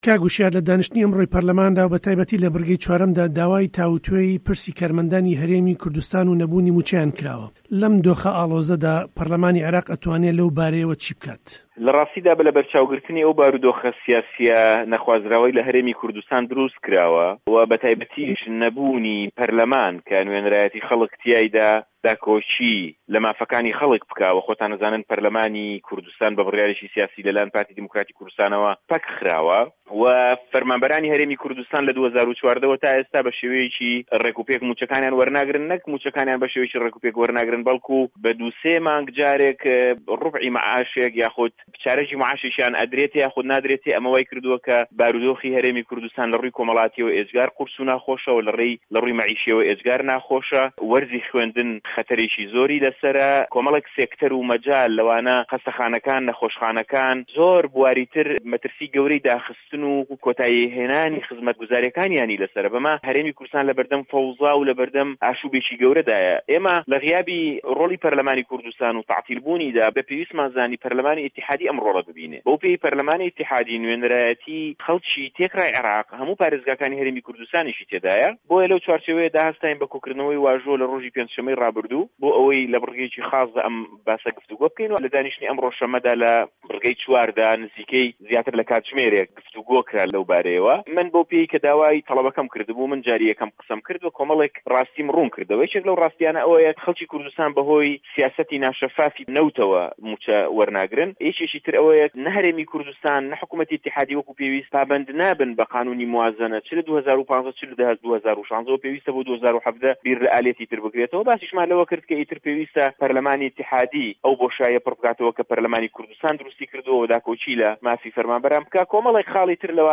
له‌مباریه‌وه‌ ده‌نگی ئه‌مریکا گفتووگۆی له‌گه‌ڵ هووشیار عه‌بدوڵا ئه‌ندامی په‌رله‌مانی عێراق کردوه‌ که‌ داوکاری تاوتوێکردنی ئه‌مپرسه‌ی له‌په‌رله‌مانی عێراق خستوه‌ته‌ ڕوو.
گفتووگۆ له‌گه‌ڵ هوشیار عه‌بدوڵا